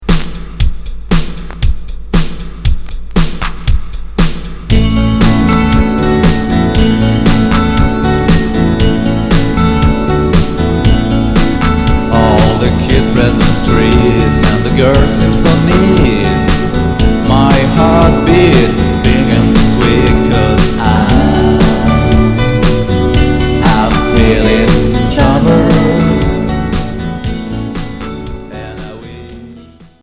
Pop rock 60's Premier 45t retour à l'accueil